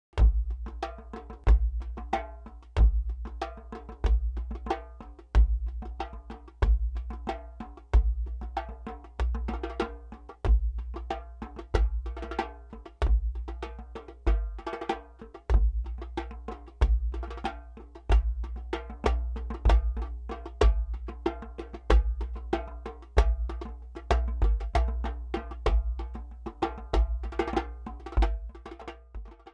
Hear the difference here.
The drums are on
separate stereo channels
TwoDjemb.mp3